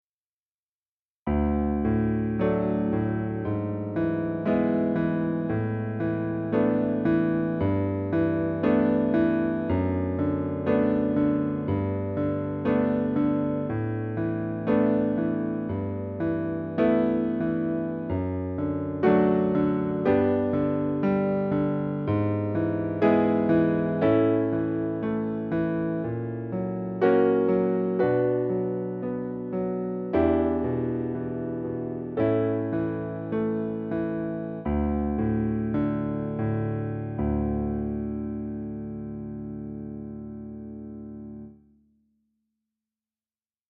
ドのみ